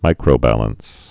(mīkrō-băləns)